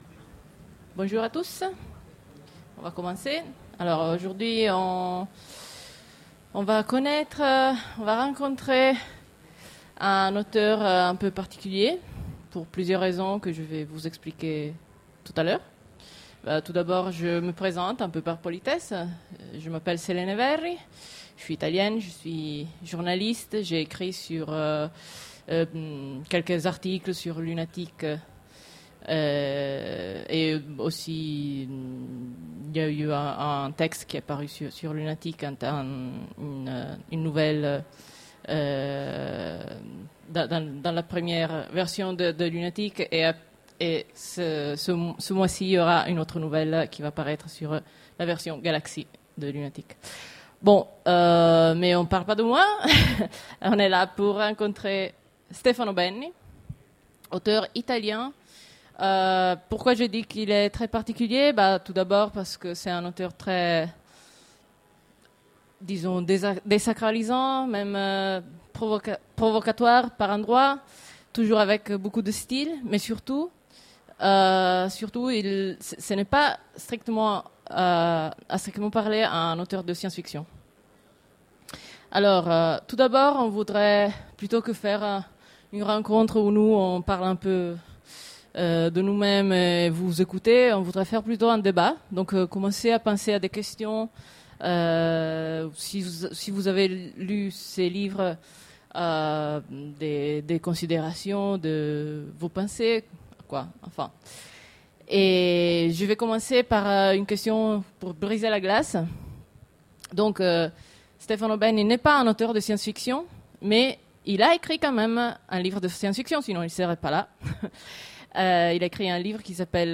Utopiales 13 : Conférence Rencontre avec Stefano Benni
- le 31/10/2017 Partager Commenter Utopiales 13 : Conférence Rencontre avec Stefano Benni Télécharger le MP3 à lire aussi Stefano Benni Genres / Mots-clés Rencontre avec un auteur Conférence Partager cet article